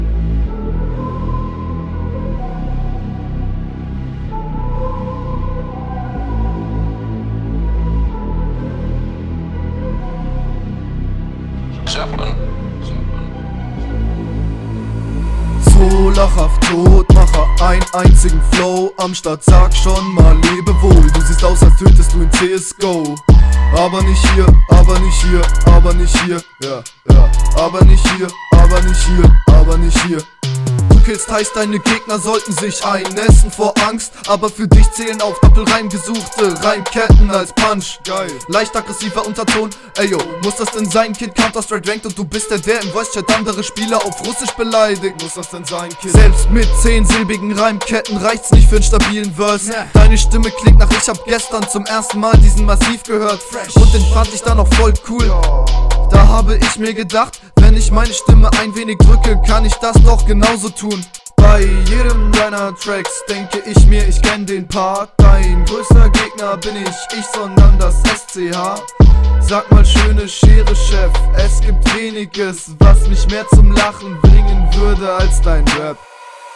Sehr Stylisch gerappte Runde
Cooler Einstieg, lädt zum mitnicken ein.